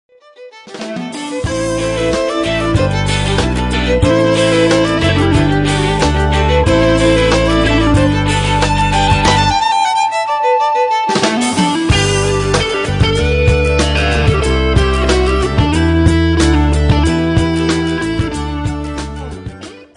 Celtic Rock